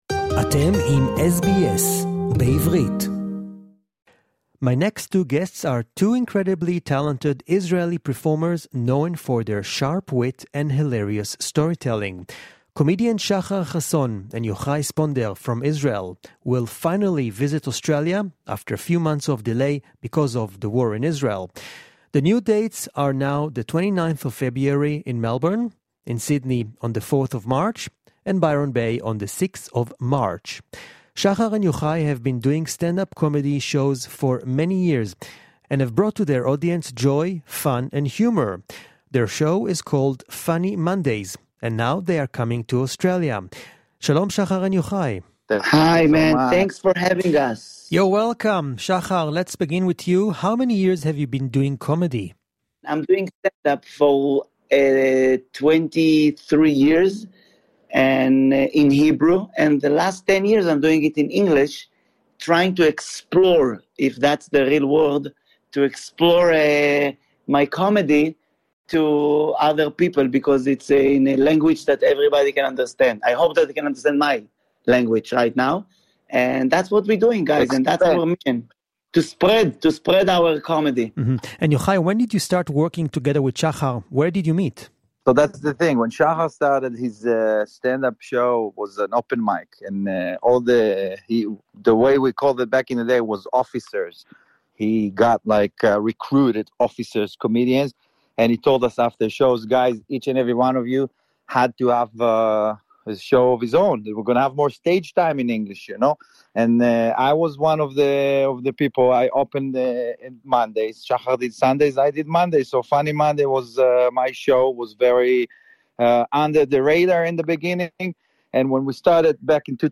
Stand up comedians Shahar Hason and Yohay Sponder in an exclusive interview for SBS Shalom Australia in English.